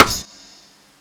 Snares
Rimshe_2.wav